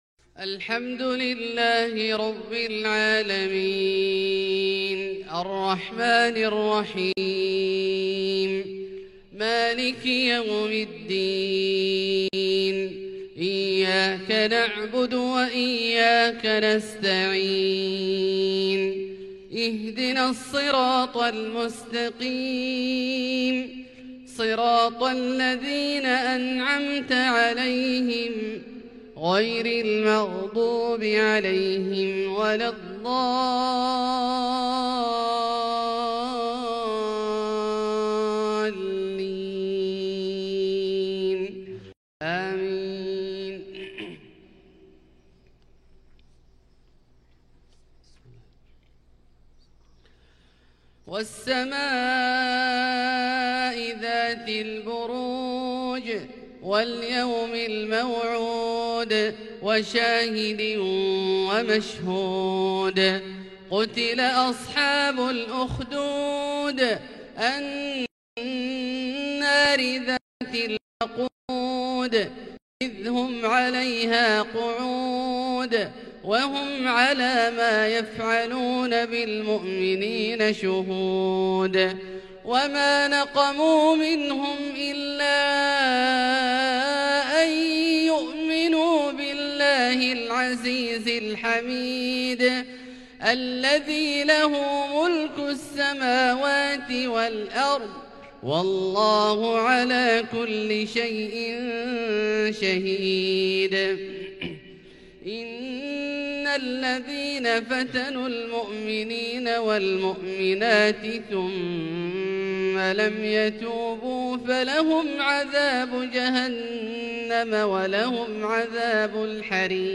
فجر الخميس 8-6-1442 هــ من سورتي البروج و الطارق | Fajr prayer from Surat Al-Burooj && At-Taariq 21/1/2021 > 1442 🕋 > الفروض - تلاوات الحرمين